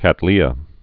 (kătlē-ə)